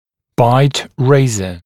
[baɪt ˈreɪzə] [байт ˈрэйзэ] устройство для повышения прикуса, разобщения прикуса